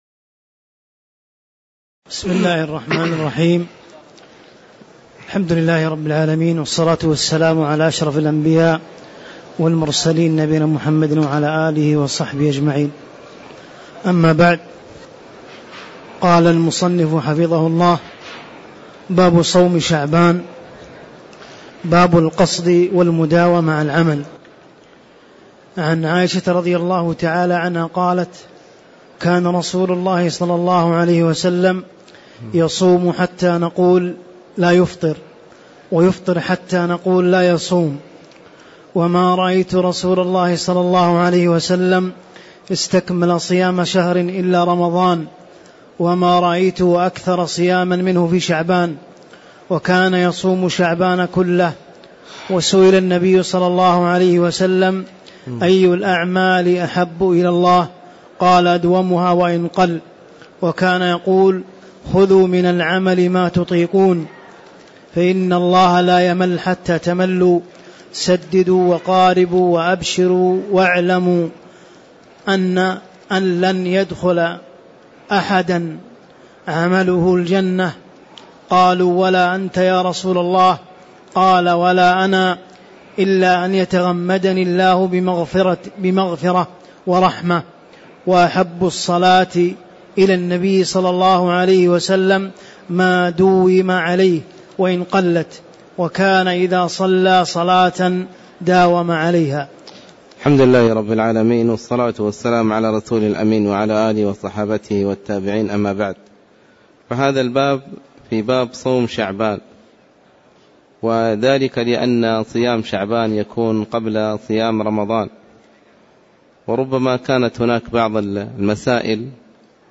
تاريخ النشر ٢١ شعبان ١٤٣٧ هـ المكان: المسجد النبوي الشيخ